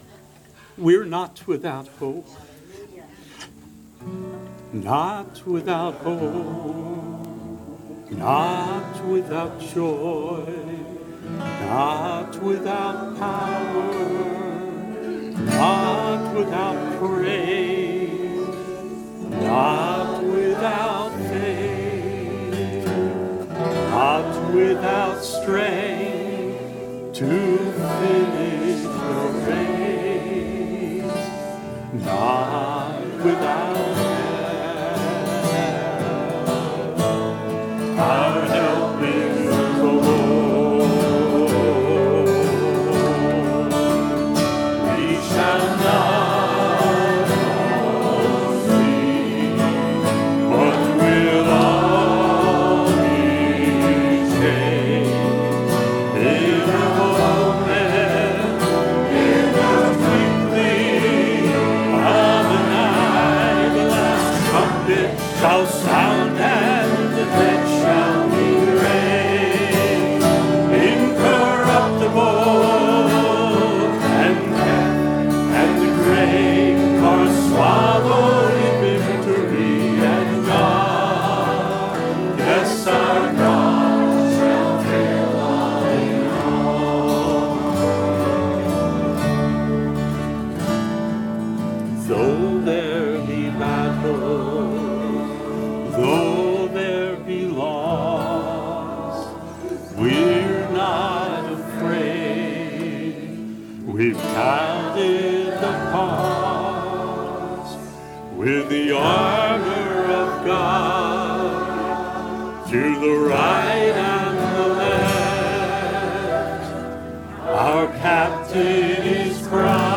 Shepherds Christian Centre Convention